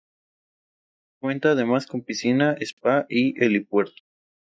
Read more spa Frequency C1 Pronounced as (IPA) /esˈpa/ Etymology Unadapted borrowing from English spa.